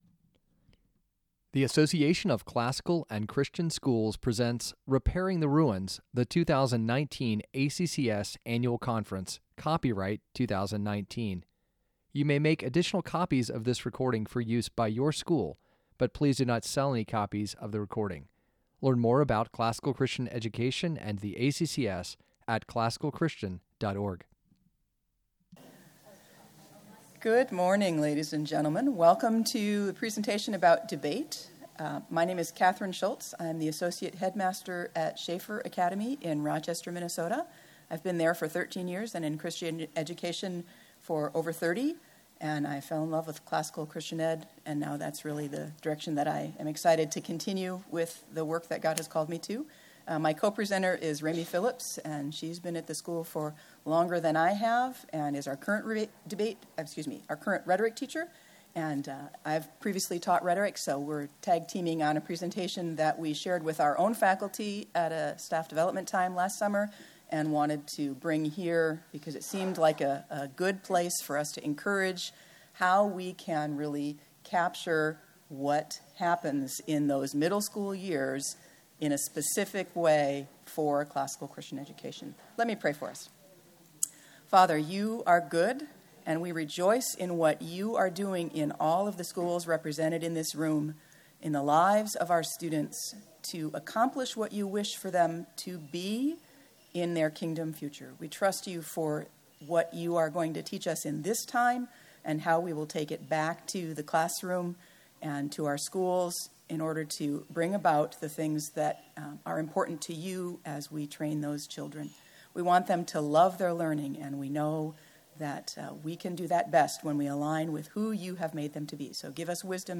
2019 Workshop Talk | 01:02:13 | All Grade Levels, Athletics & Extracurricular, Rhetoric & Composition
Additional Materials The Association of Classical & Christian Schools presents Repairing the Ruins, the ACCS annual conference, copyright ACCS.